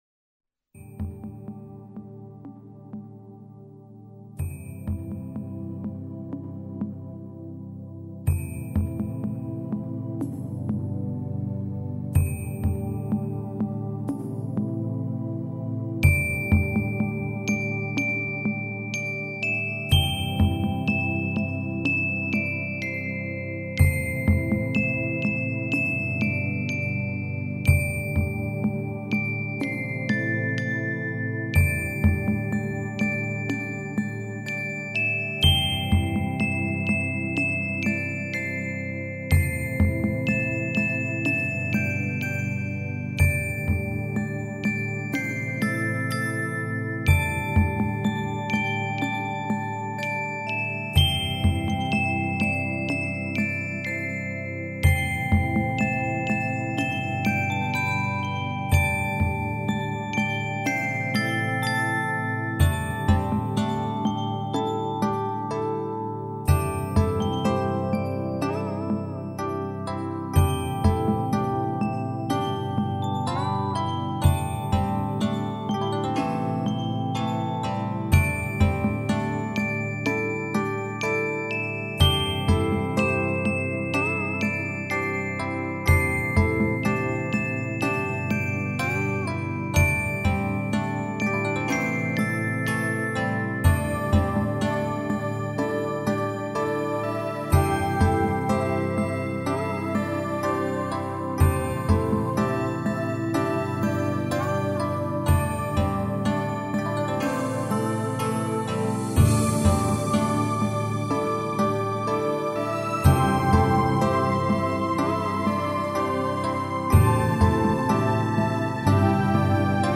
水晶琴闪烁着最清心无欲的音符，以简单、真朴的旋律，